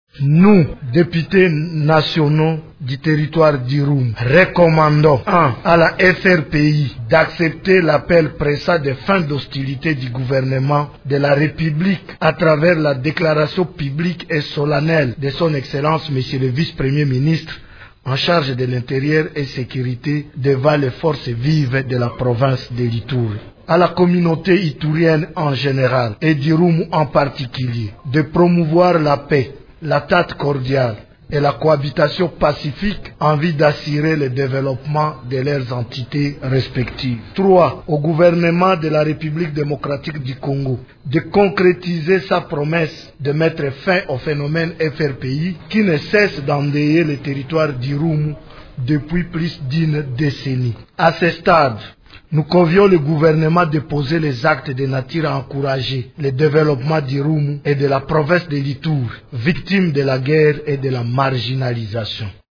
Vous pouvez écouter la déclaration de ces élus lue par leur porte-parole.